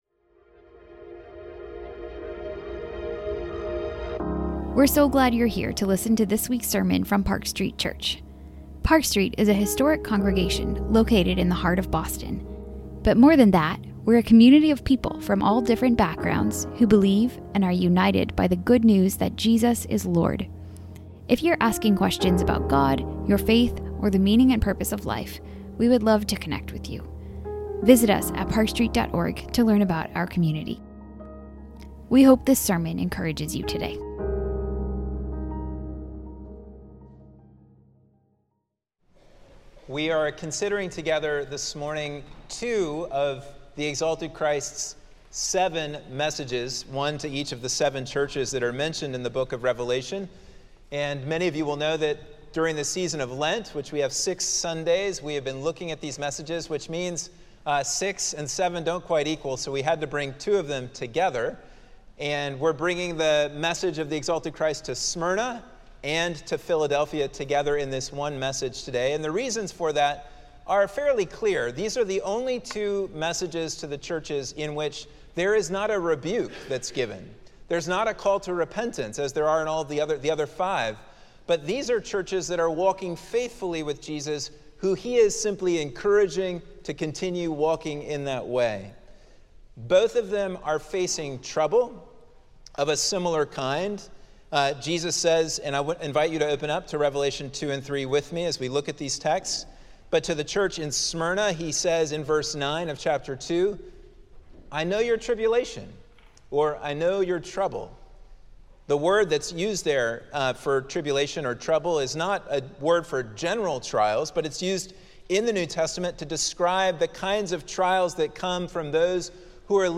Sermons - Park Street Church